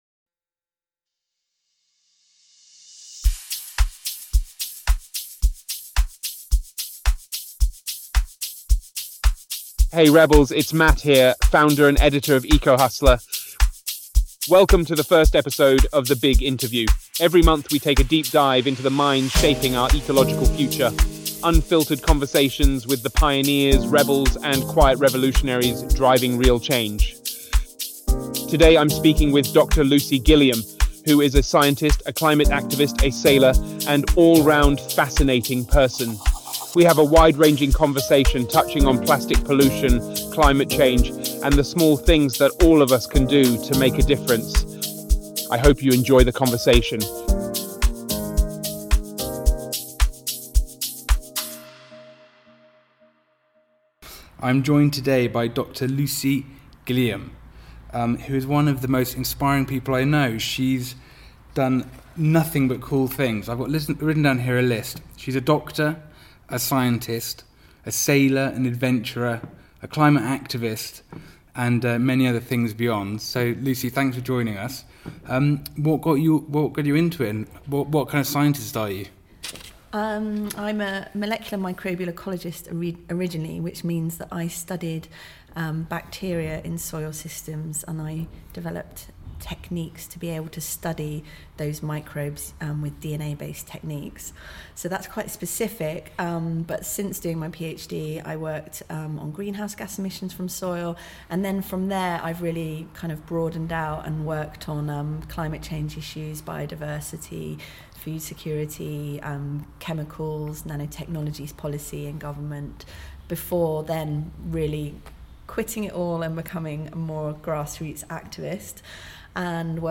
THE BIG INTERVIEW